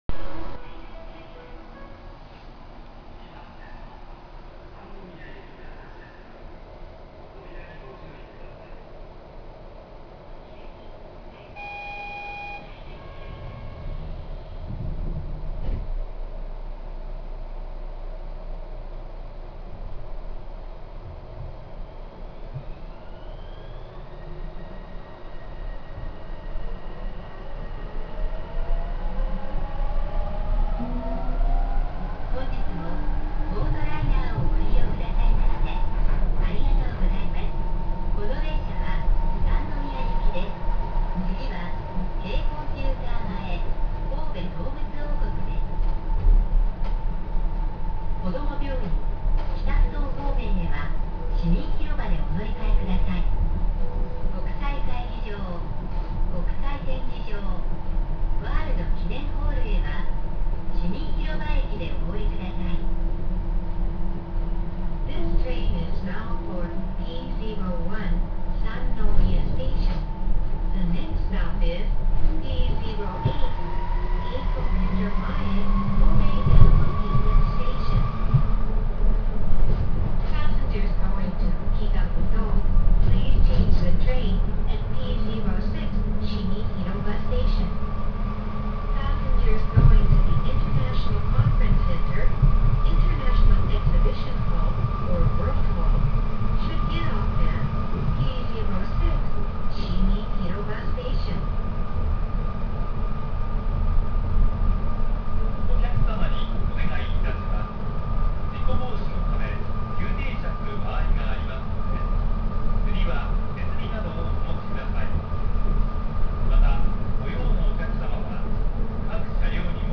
ŽO•HIGBT‚ðÌ—pB‘S‚Ä‚ÌŽÔ—¼‚ªƒ‚[ƒ^[ŽÔ‚Å‚ ‚èA_ŒË‹ó`‚Í‘¼‚Ì‹ó`‰w‚Æ”ä‚×‚Ä¬ŽG‚µ‚È‚¢‚Ì‚ÅŽû˜^‚àŠy‚Å‚·B